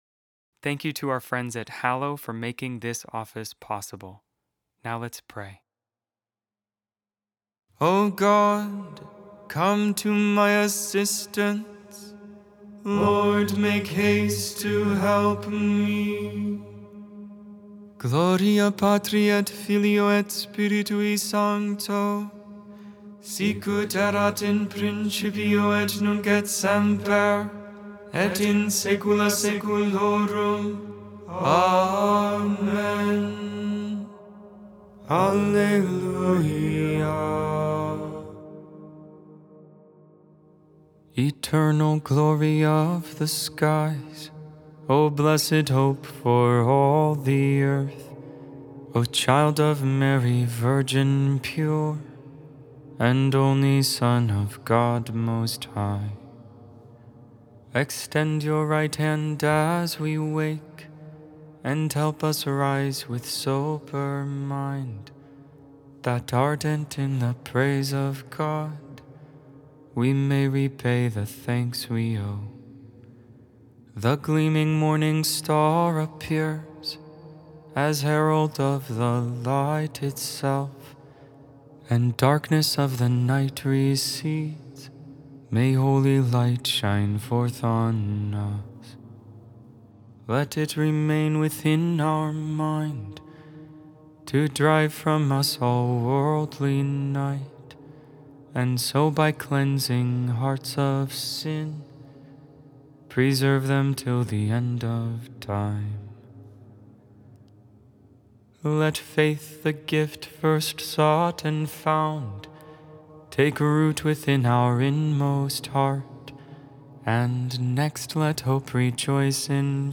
Lauds, Morning Prayer for the 29th, Friday in Ordinary Time, October 24, 2025.Made without AI. 100% human vocals, 100% real prayer.